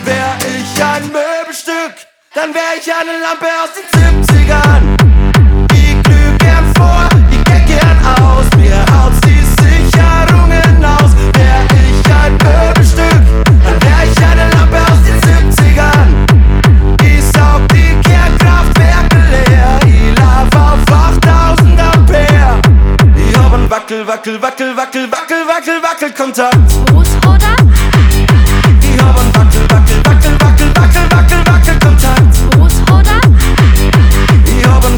Жанр: Поп / Электроника